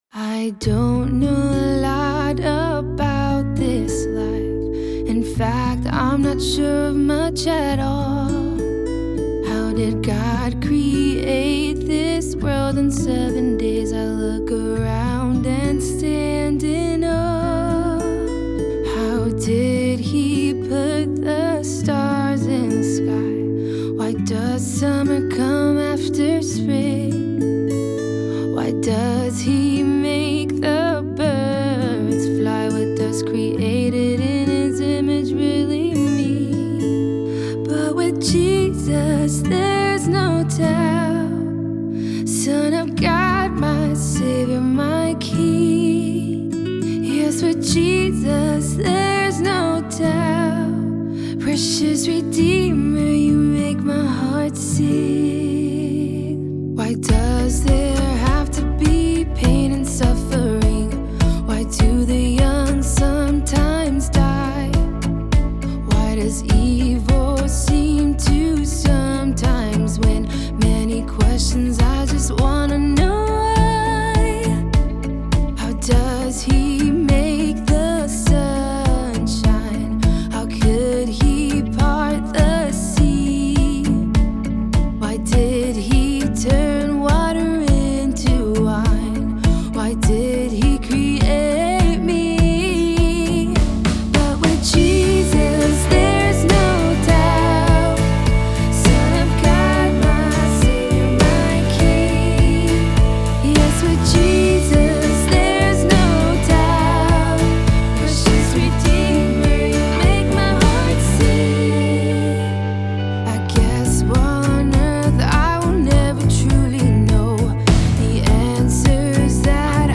While this song takes a little while to get started, things really kick in once the drums and muted double-tracked guitars enter. This song's production is fantastic, and it's evident that the composer has a good understanding of how the instrumentation/arranging works in contemporary Christian music.